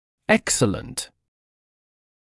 [‘eksələnt][‘эксэлэнт]отличный, прекрасный